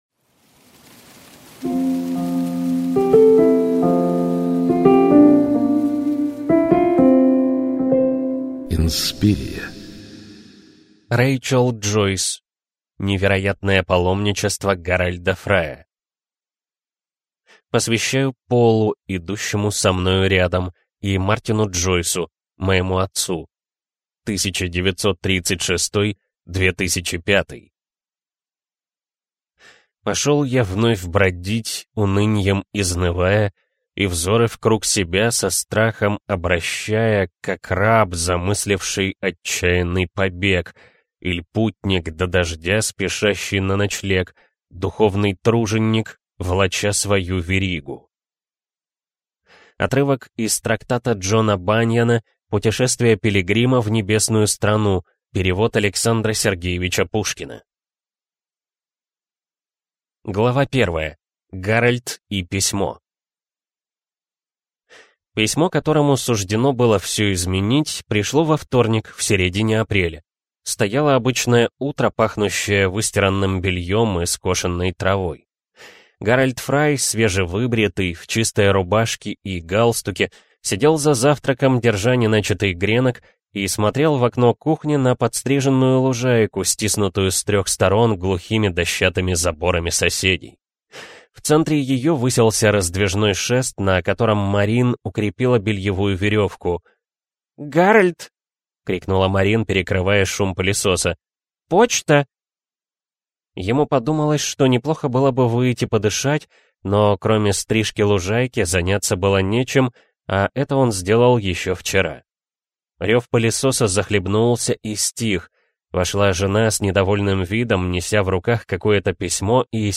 Аудиокнига Невероятное паломничество Гарольда Фрая | Библиотека аудиокниг